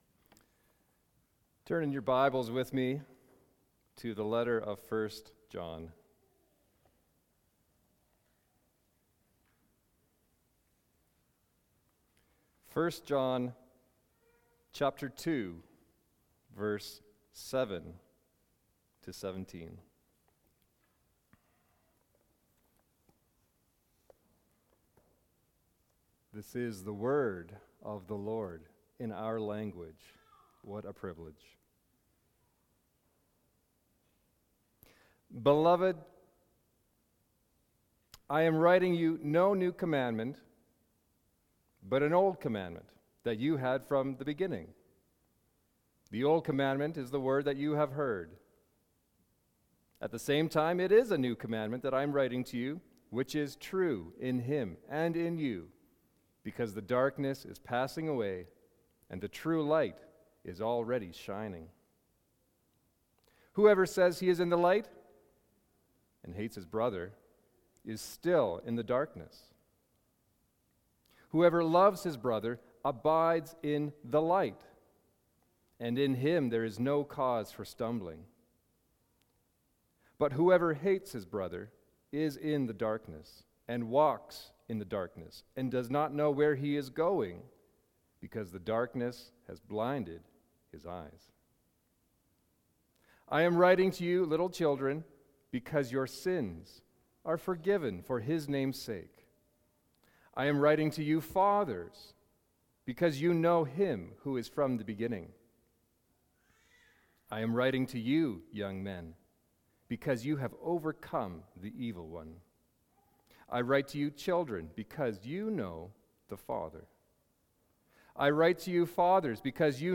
Audio recordings of teaching from Calvary Grace Church of Calgary.